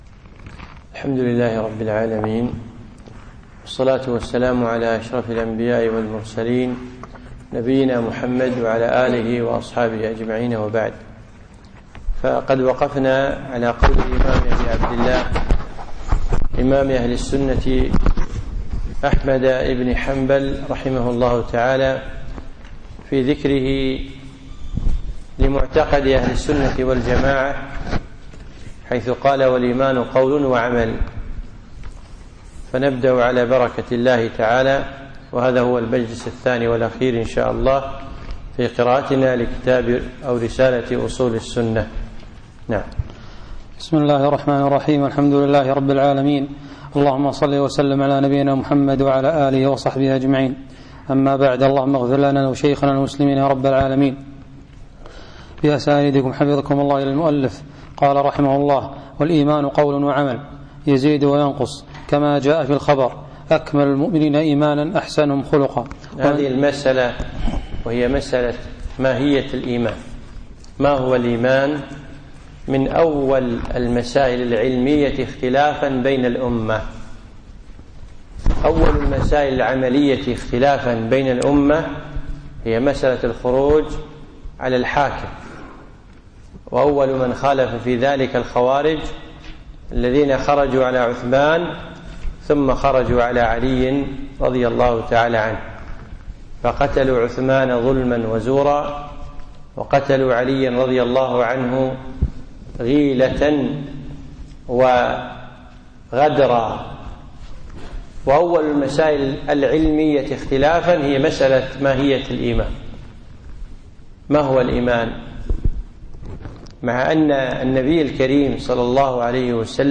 يوم الجمعة 30 ذي العقدة 1437هـ الموافق2 9 2016م في مسجد الهاجري الجابرية